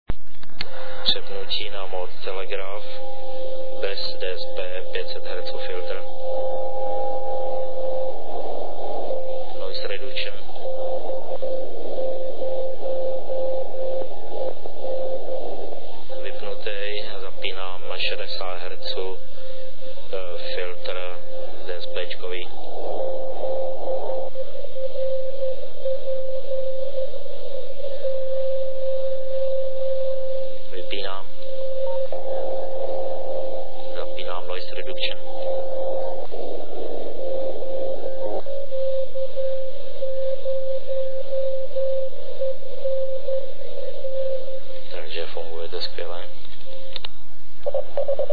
Testy probíhaly v době kolem 15. hodiny a byl poslouchán zašumělý QRP maják OK0EN na kmitočtu 3600.10 KHz.
Zde je potlačení tak max 25 dB a to může být někdy málo.